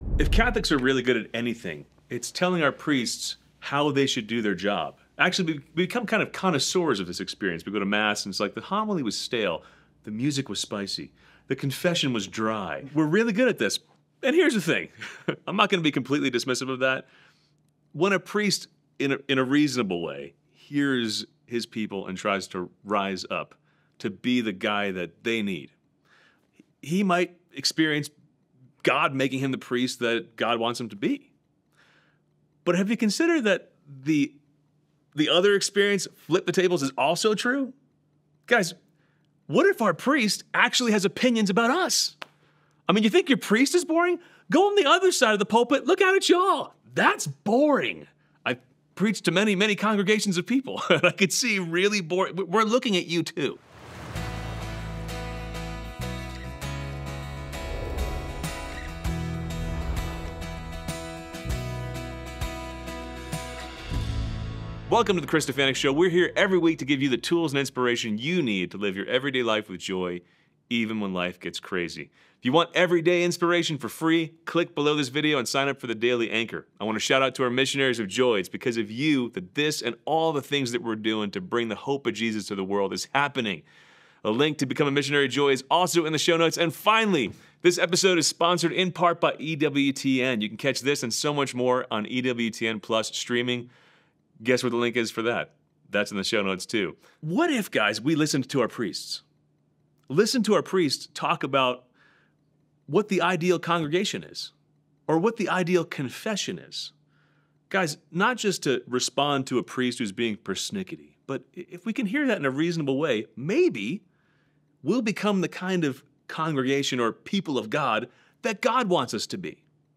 Our conversation is authentic, funny, and convicting